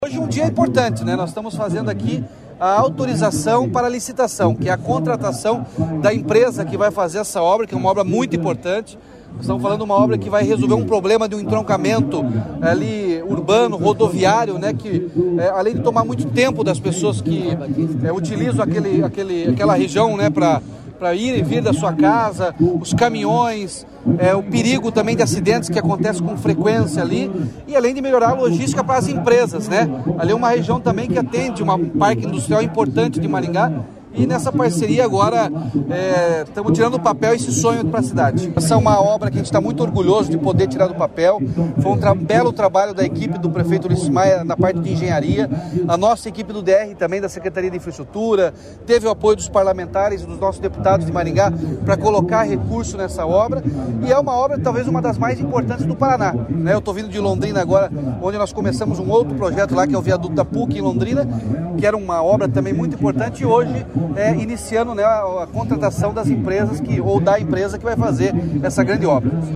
Sonora do governador Ratinho Junior sobre o lançamento do edital de licitação para o Novo Trevo Catuaí, em Maringá